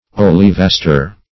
Search Result for " olivaster" : The Collaborative International Dictionary of English v.0.48: Olivaster \Ol`i*vas"ter\, a. [L. oliva olive: cf. F. oliv[^a]tre.]